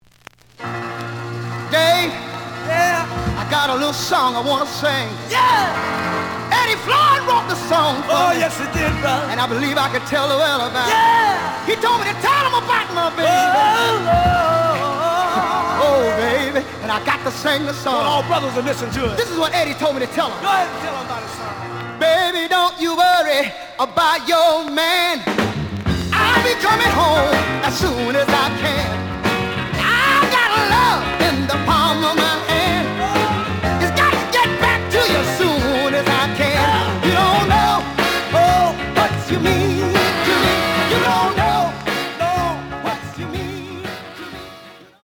The audio sample is recorded from the actual item.
●Genre: Soul, 60's Soul
Some click noise on A side due to scratches.